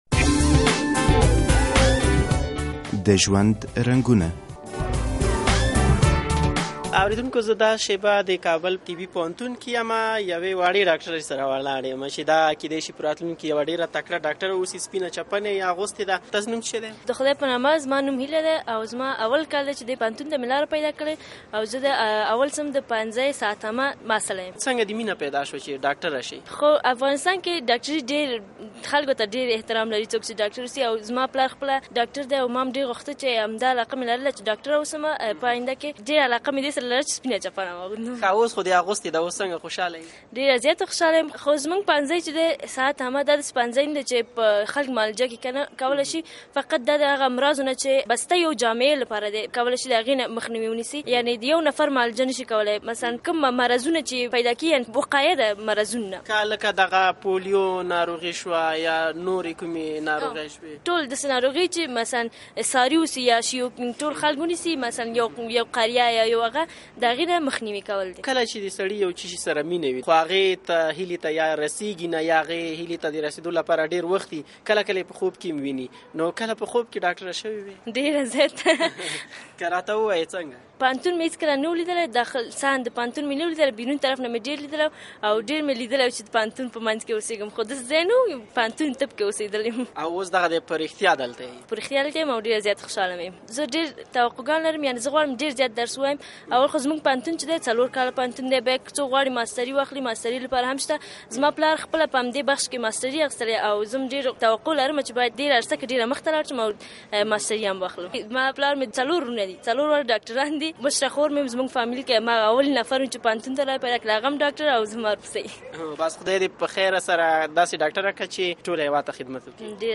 د ژوند په رنګونو کې له یوې ډاکټرې سره مرکه لرو